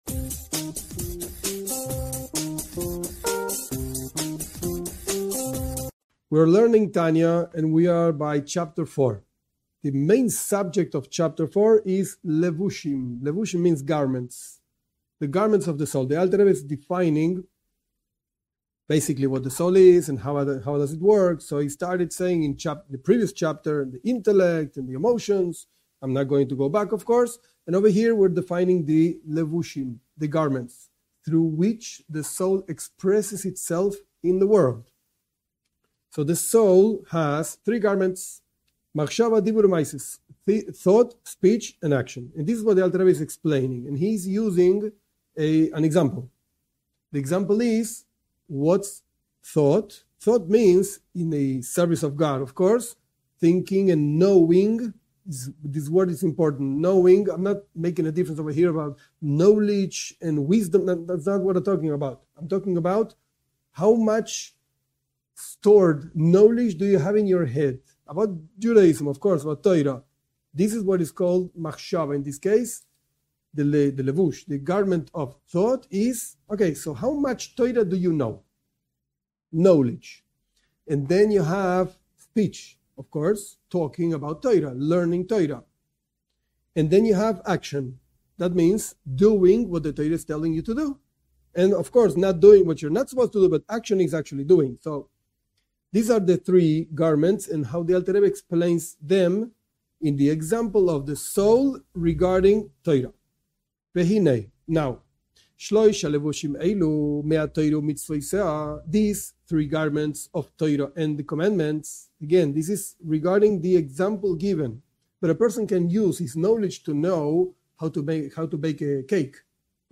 This class teaches Tanya, Book of Beinonim (intermediate people).